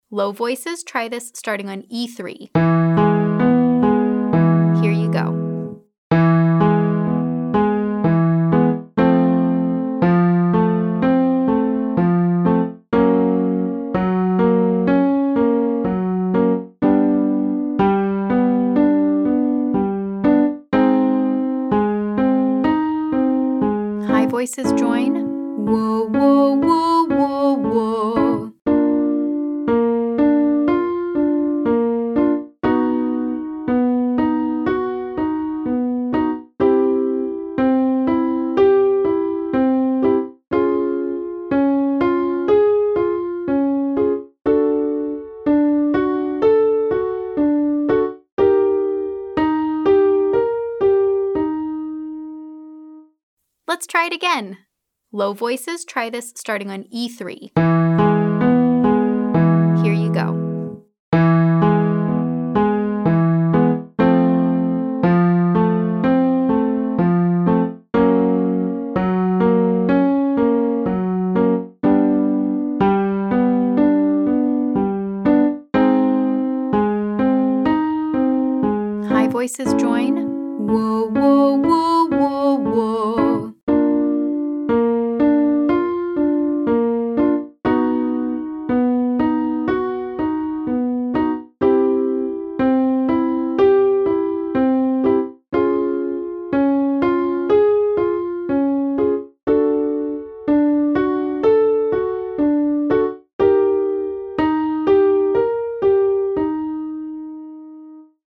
Exercises for day 4: